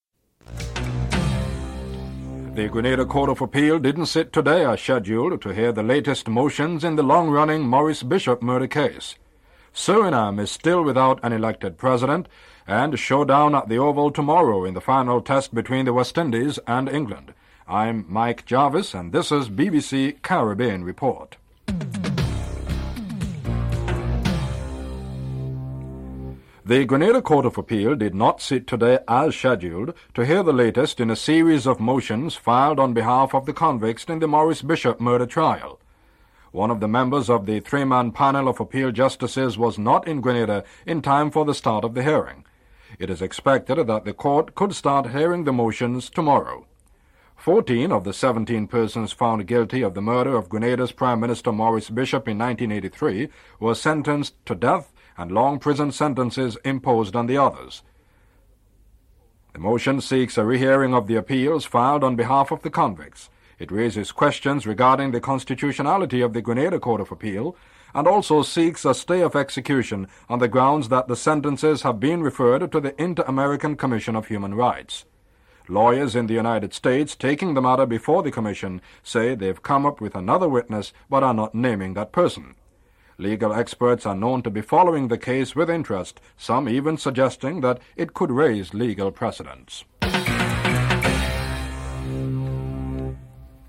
Jonathan Agnew interviews Ian Botham on the upcoming match and Vivian Richards on his pending retirement.